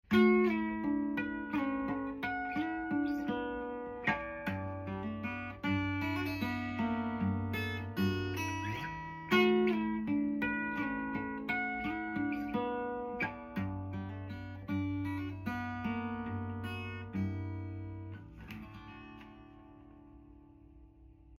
😌🙏 Here are some cool harmonic vibes mixed in with some sweet finger picking. I’m playing this little ditty on my Martin 000-17 It’s unplugged but I have an awesome Dearmond passive soundhole pickup as well as some under the saddle transducers which are also passive.